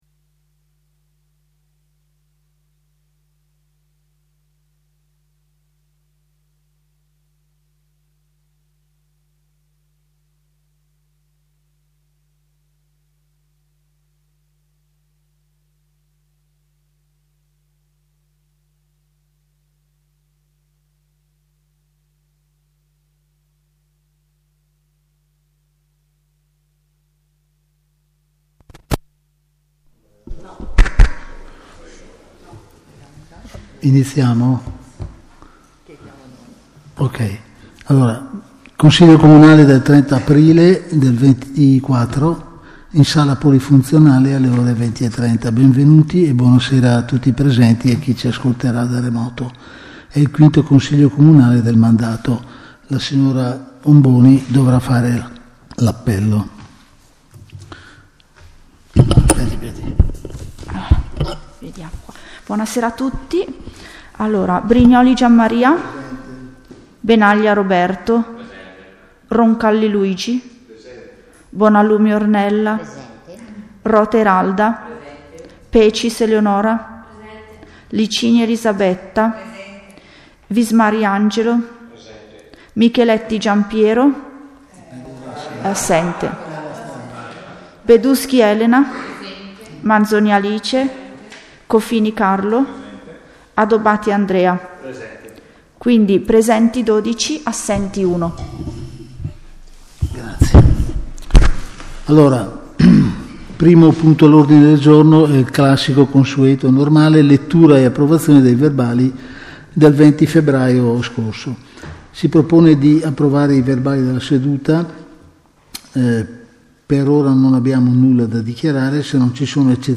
Registrazione del consiglio comunale del 30 Aprile 2024 - Comune di Paladina
È disponibile la registrazione audio in formato MP3 del Consiglio Comunale del 30 Aprile 2024.